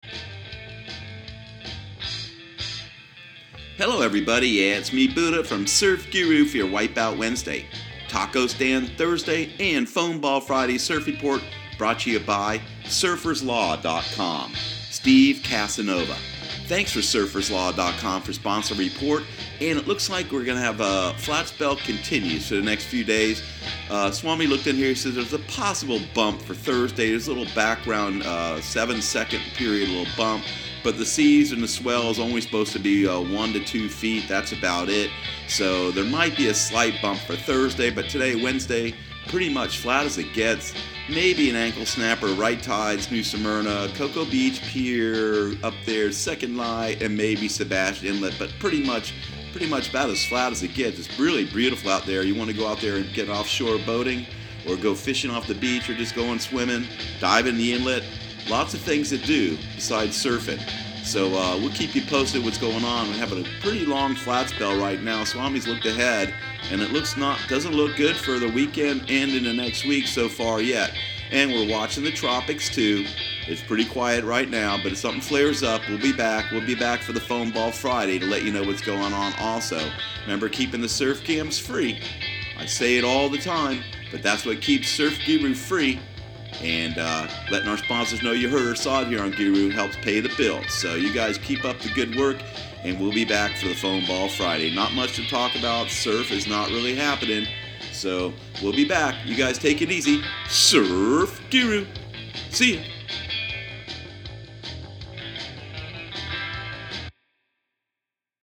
Surf Guru Surf Report and Forecast 06/06/2018 Audio surf report and surf forecast on June 06 for Central Florida and the Southeast.